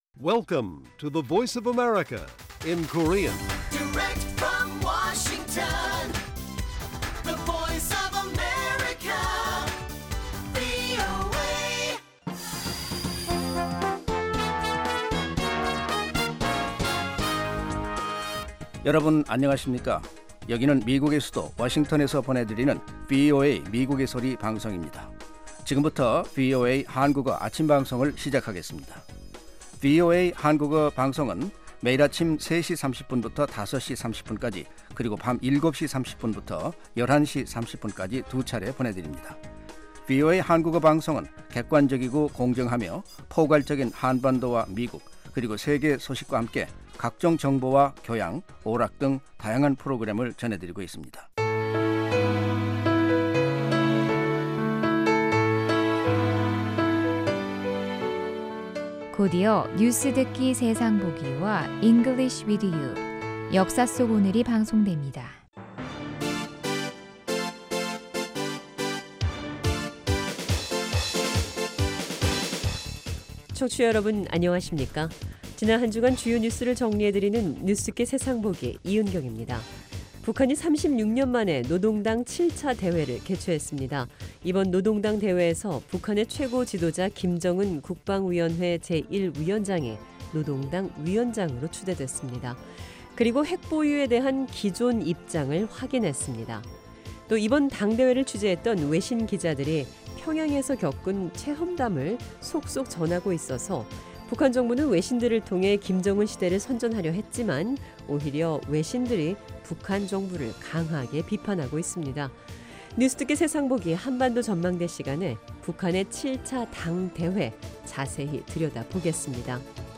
VOA 한국어 방송의 일요일 오전 프로그램 1부입니다.